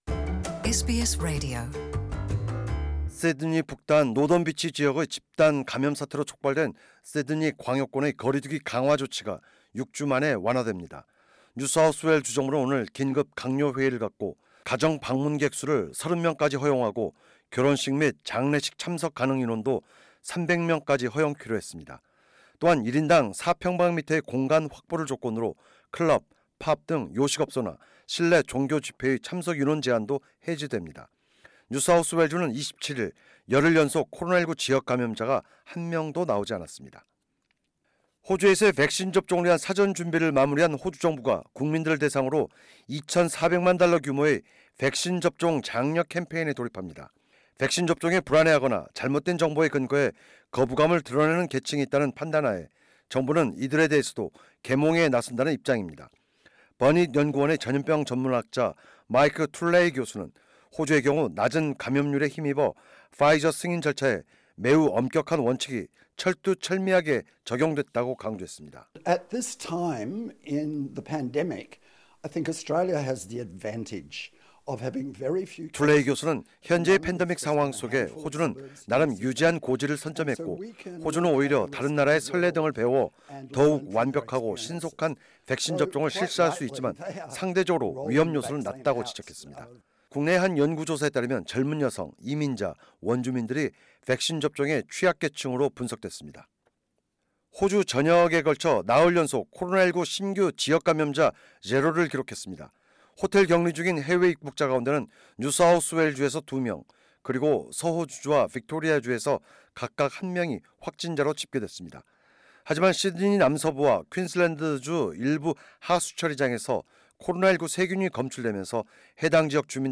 2021년 1월 27일 수요일의 코로나19 뉴스 업데이트입니다.